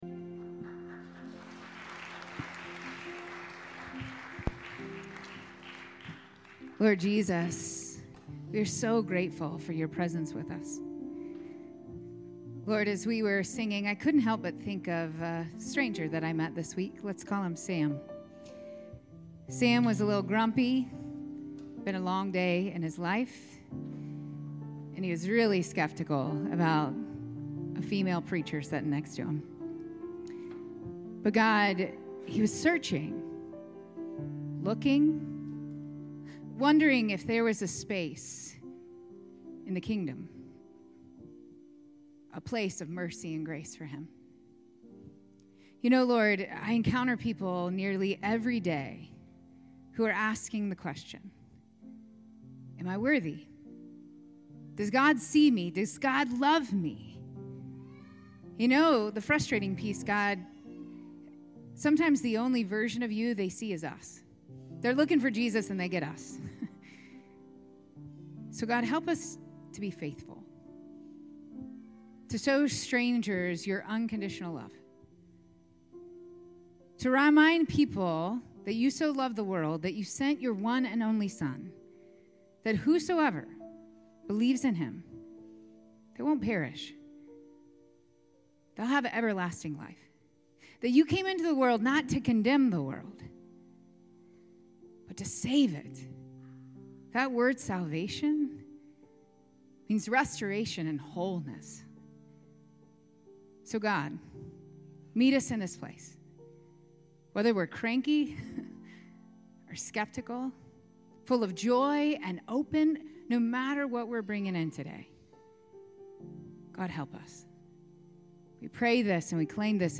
9:30 am Contemporary Worship Service 04/06/25